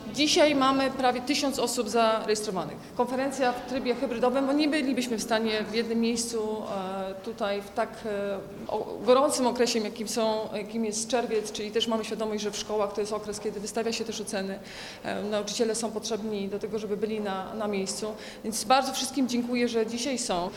W trosce o zdrowie psychiczne dzieci i młodzieży na Dolnym Śląsku odbyła się w czwartek (12 czerwca) Wojewódzka Konferencja dla dyrektorów szkół i nauczycieli, poświęcona przeciwdziałaniu kryzysom emocjonalnym wśród najmłodszych.
– Ta liczba zarejestrowanych uczestników pokazuje, jak temat jest ważny, wyczekiwany i potrzebny do usystematyzowania wszystkich dobrych praktyk, omówienia ich, dopracowania oraz udostępnienia wszystkim dla poprawy opieki – podkreśla wojewoda Anna Żabska.